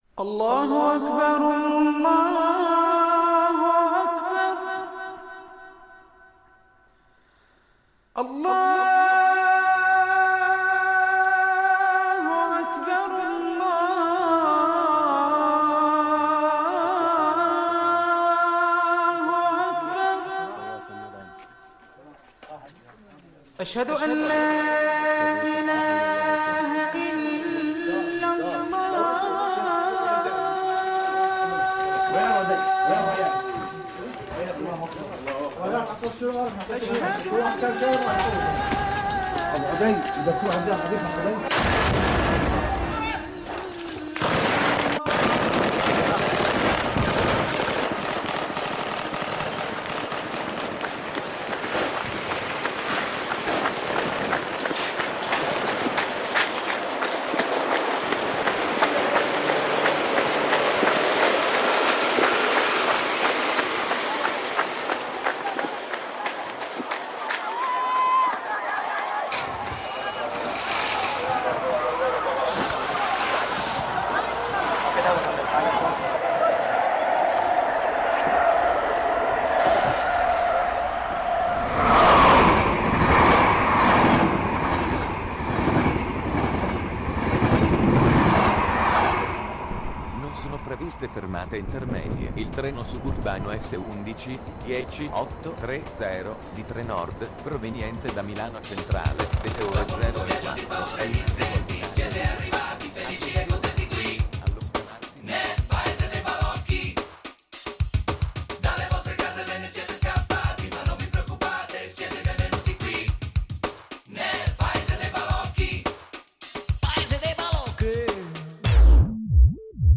Il documentario ha voluto andare “dentro” l’ospedale e sentire chi dentro l’ospedale lavora: il direttore sanitario, il responsabile del Pronto soccorso, gli infermieri, le capo-reparto. Sono le loro voci che raccontano come, nell’avamposto, i migranti vengono curati.